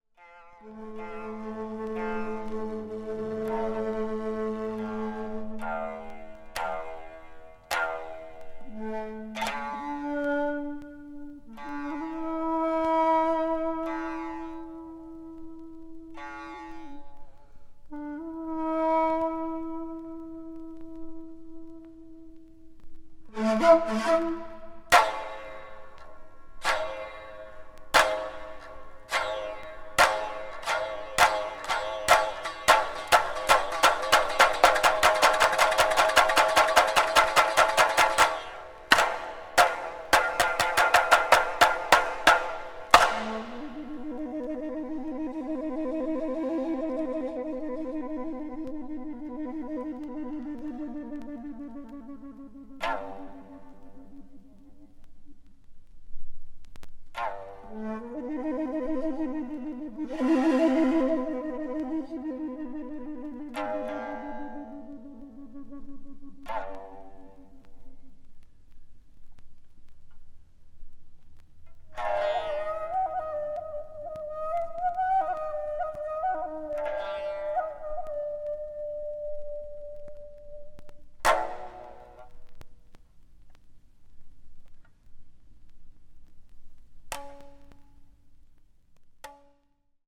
20th century   contemporary   modern classical   post modern